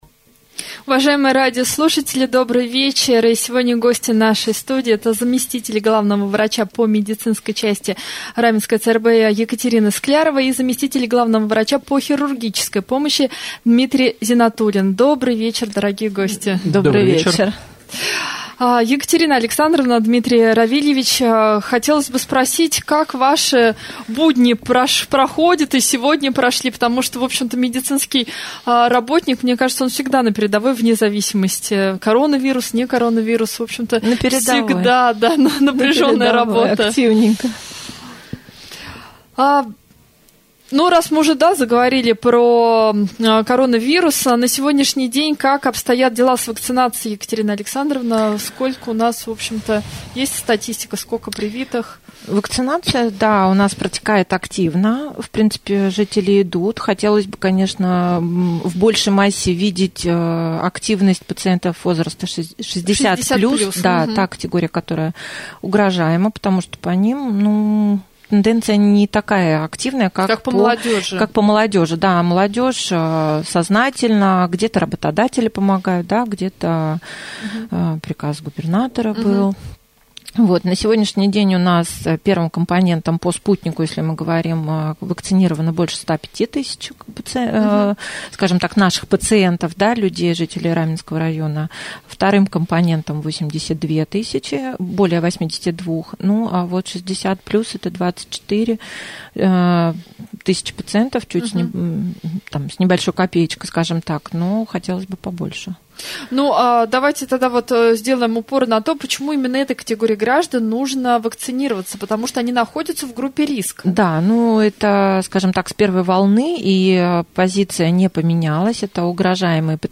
prjamoj-jefir-1.mp3